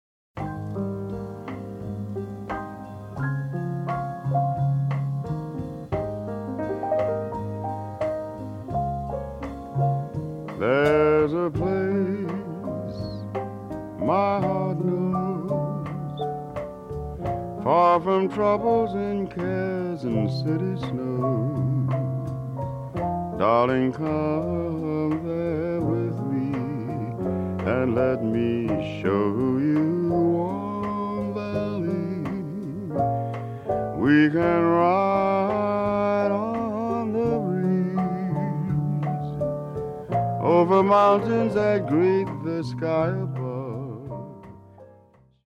ラジオ番組のために録音された音源なのだそう。